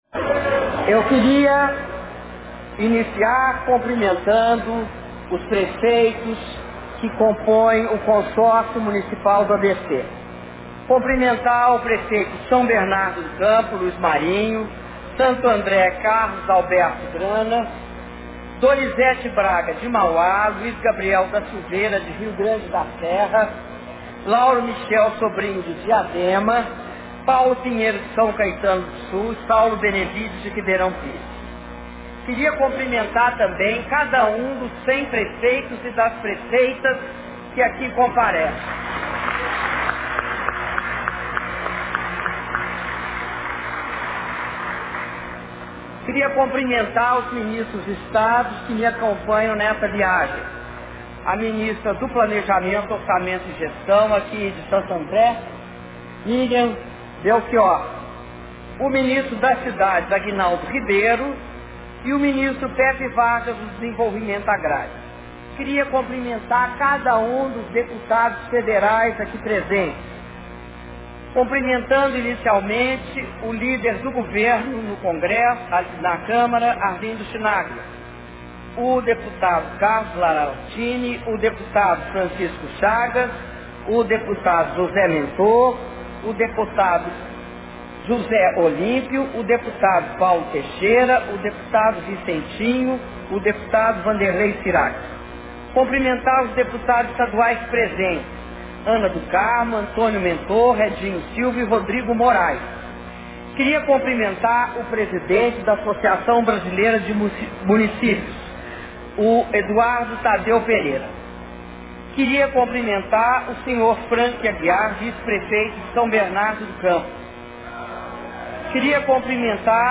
Áudio do discurso da presidenta da republica Dilma Rousseff durante cerimônia de anúncio de investimentos do PAC para cidades do ABC e entrega de 100 máquinas retroescavadeiras a municipios de São Bernardo do Campo SP